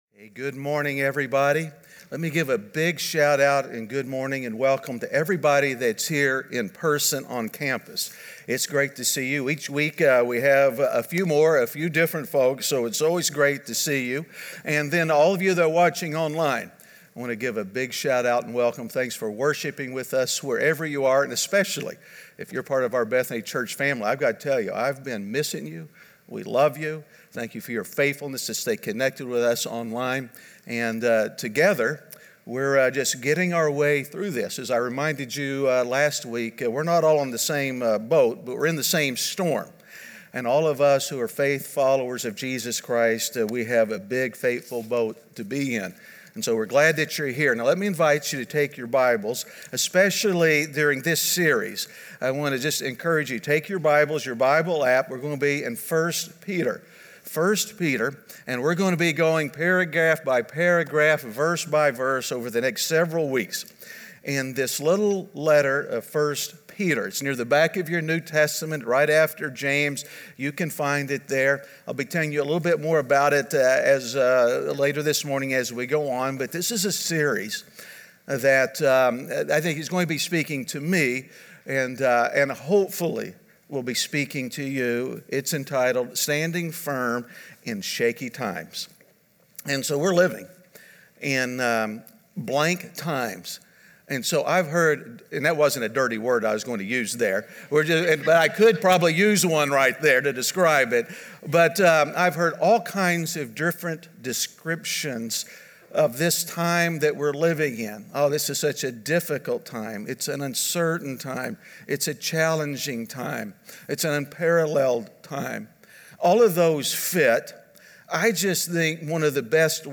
Standing Firm In Shaky Times (Week 1) - Sermon.mp3